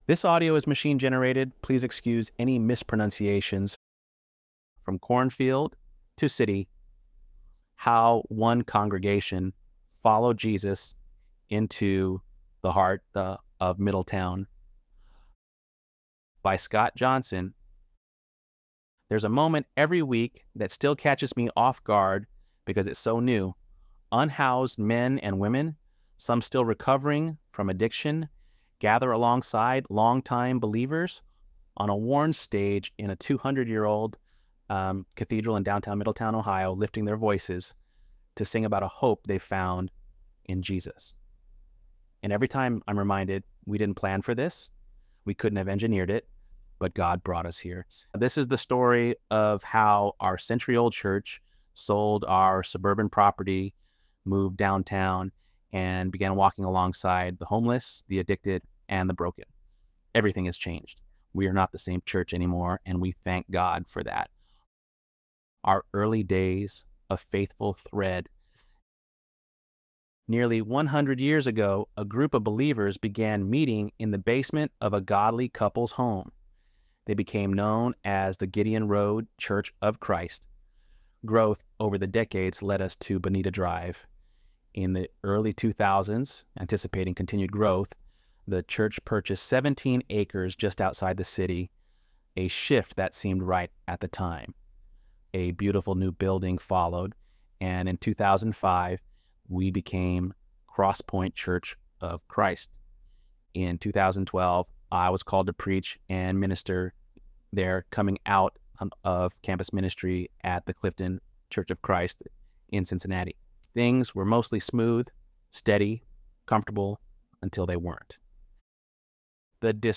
ElevenLabs_10.30.mp3